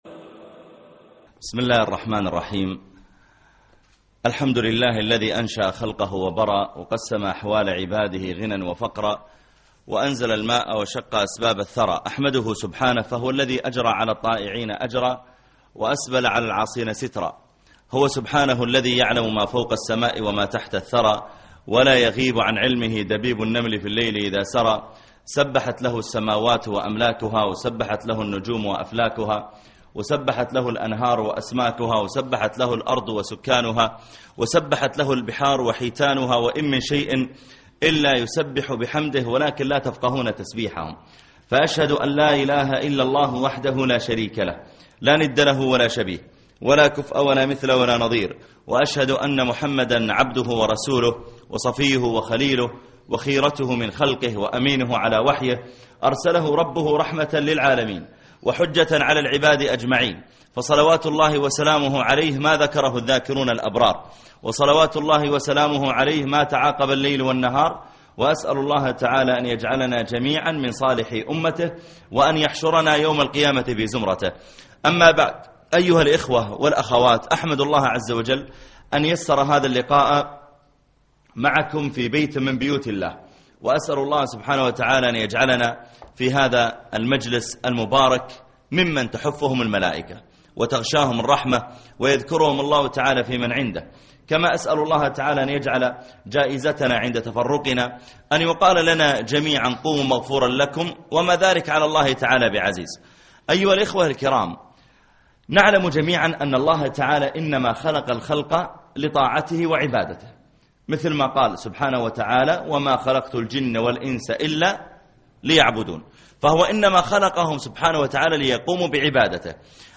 دلّني على قبرها (21/1/2012) محاضرة اليوم - الشيخ محمد العريفي